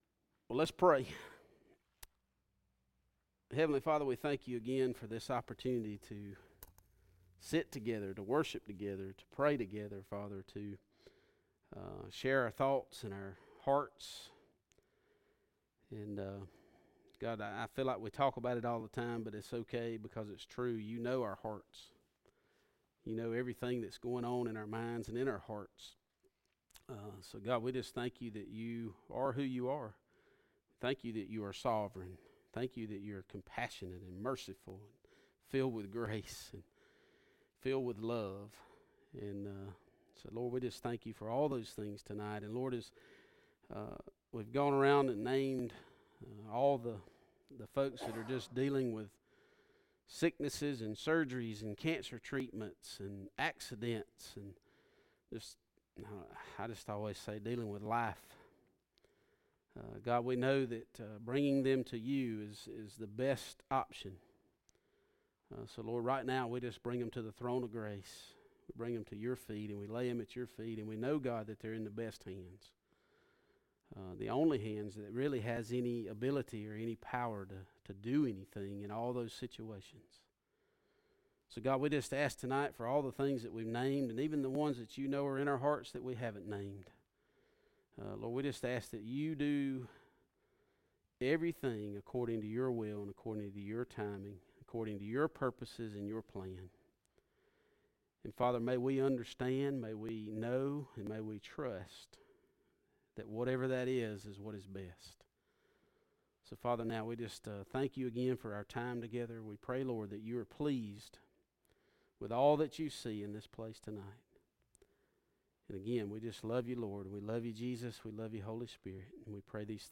Message Type - Bible Study
Occasion - Sunday Evening